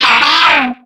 Cri de Lombre dans Pokémon X et Y.